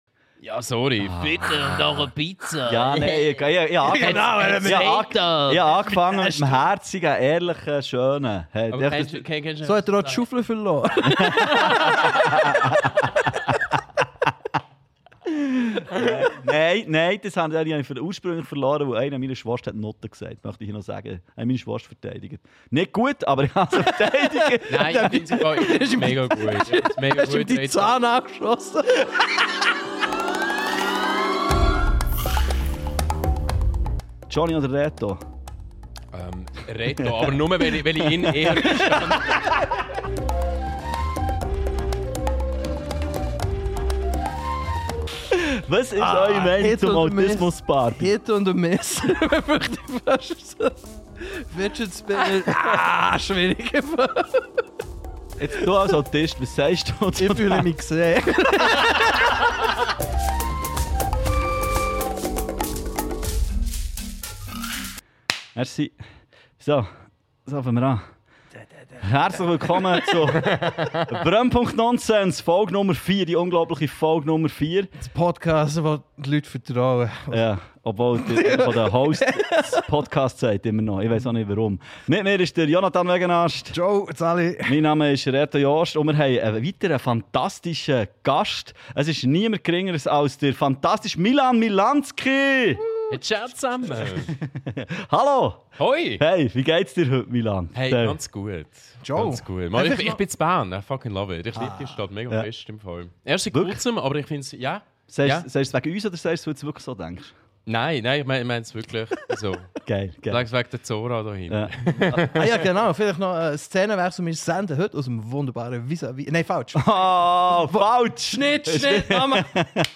Ufgno heimer die Foug dasmau im wunderbare Wohnzimmer vom Les Amis in Bärn.